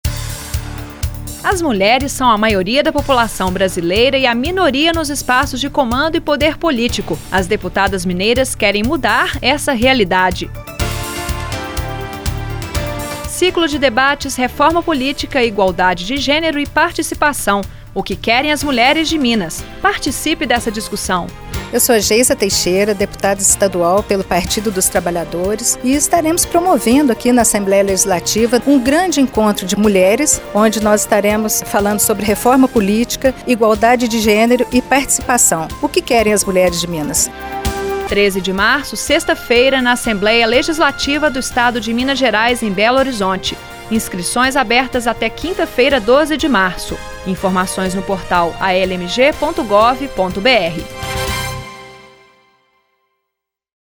Ouça o convite da deputada Geisa Teixeira, PT